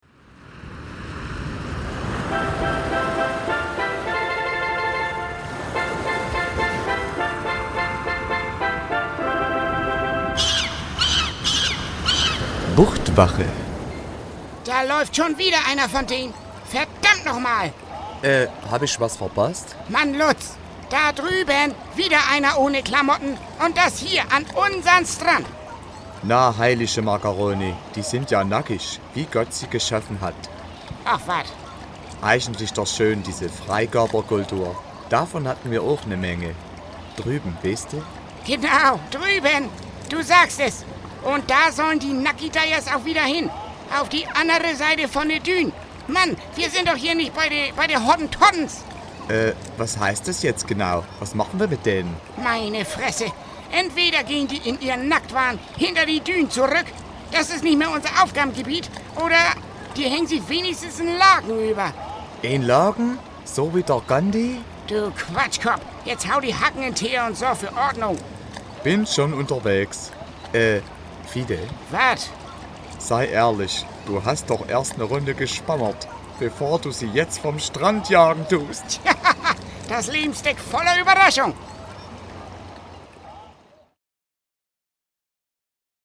BUCHTWACHE, Radiocomedy
Kurzinhalt: Ein alter und ein sächsischer Rettungsschwimmer